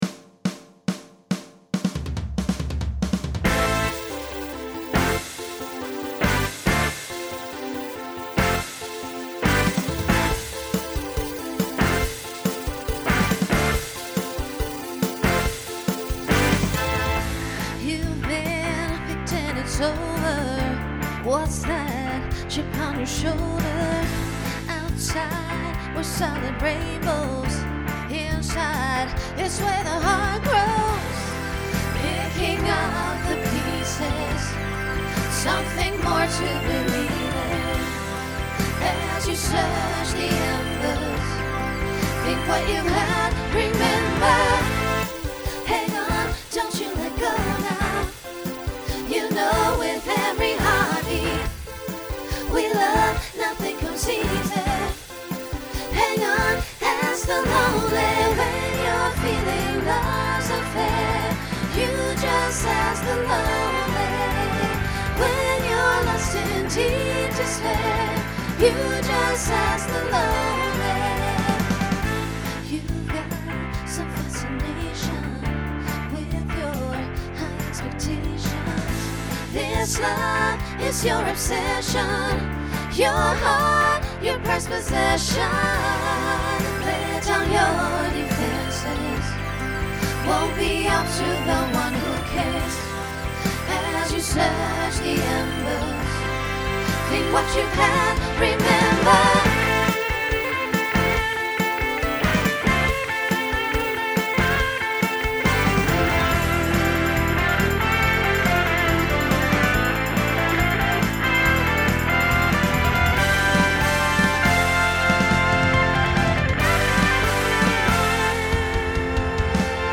Genre Rock Instrumental combo
Transition Voicing SSA